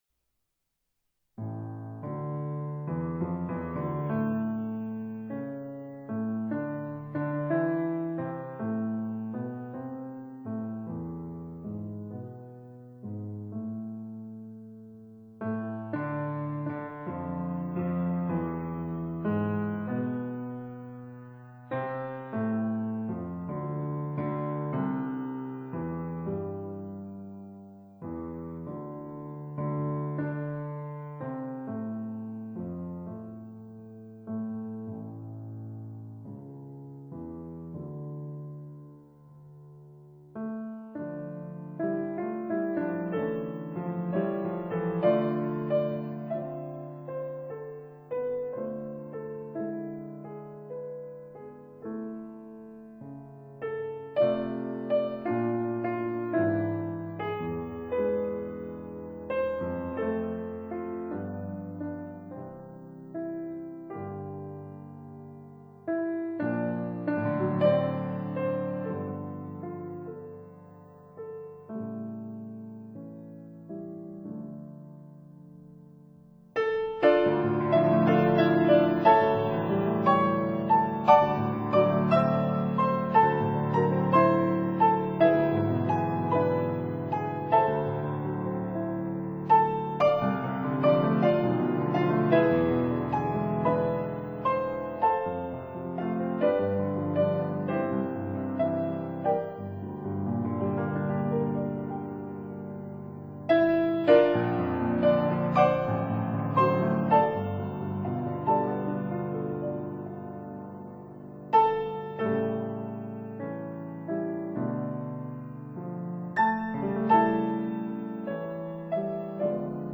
piano Date